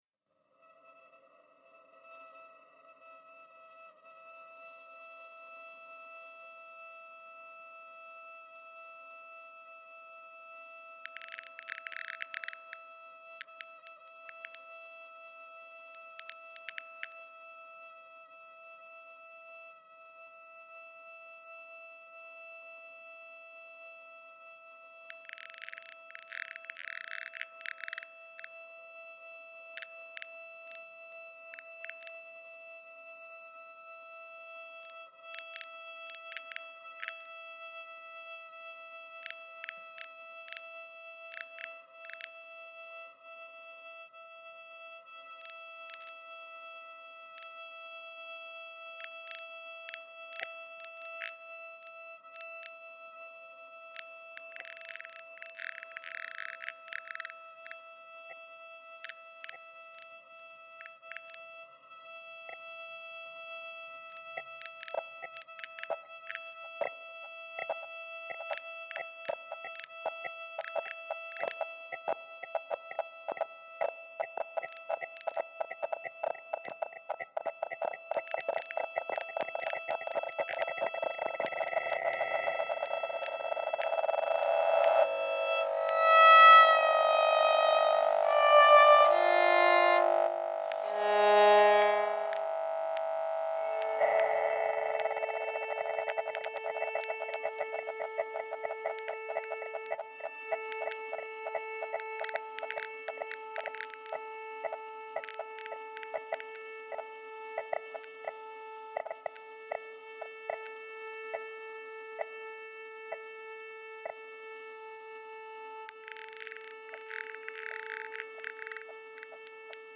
organised in seven sections between one and 4.5 minutes
violin
electronics